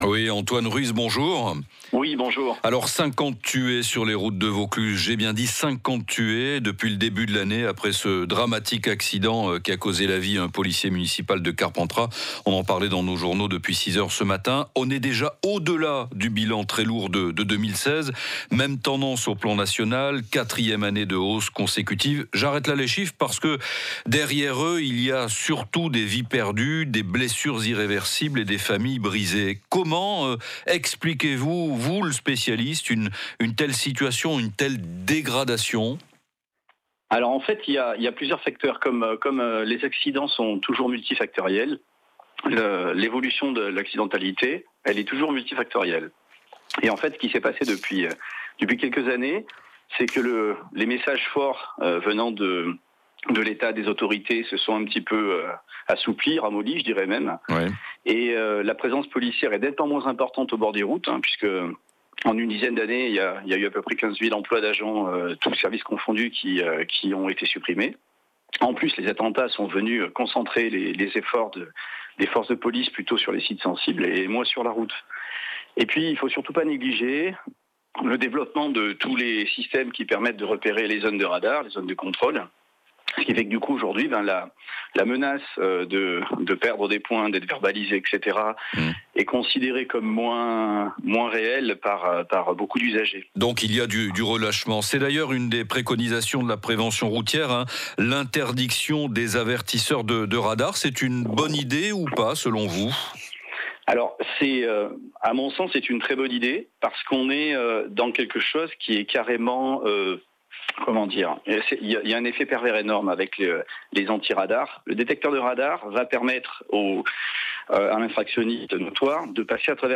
Interview © France Bleu Vaucluse